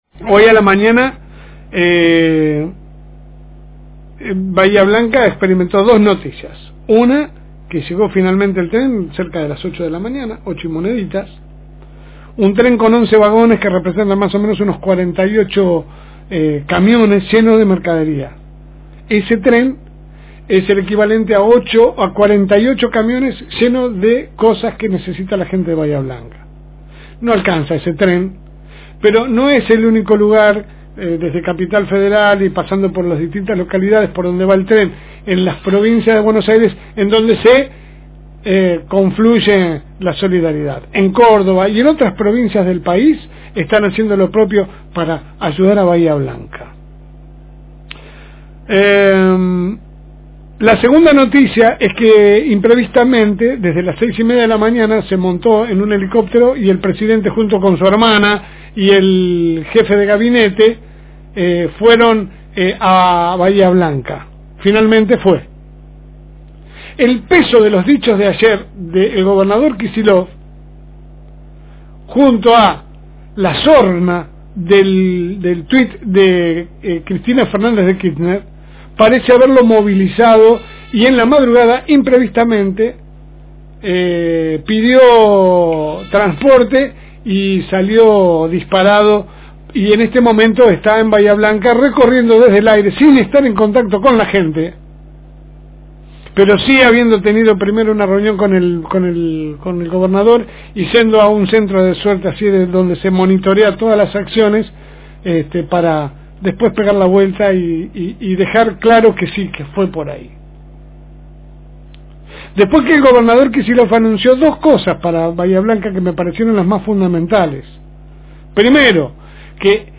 AUDIO – Editorial LSM